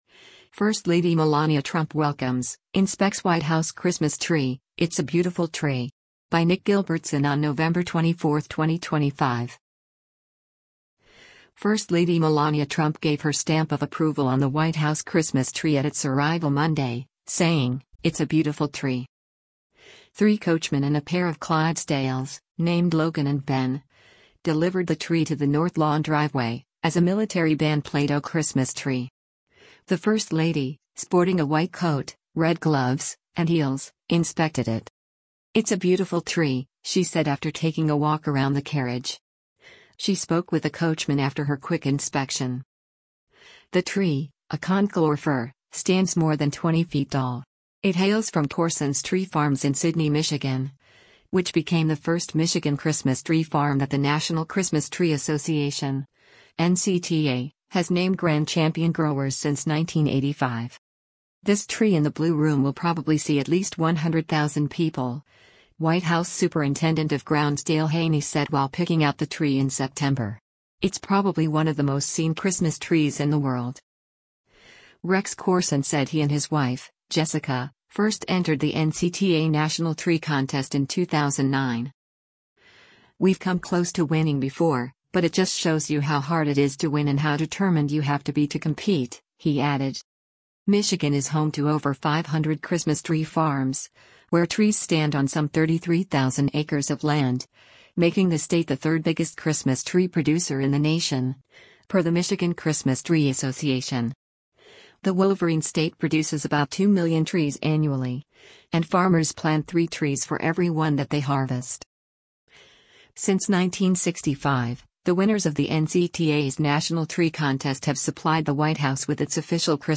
Three coachmen and a pair of Clydesdales, named Logan and Ben, delivered the tree to the North Lawn driveway, as a military band played “O Christmas Tree.”
“It’s a beautiful tree,” she said after taking a walk around the carriage.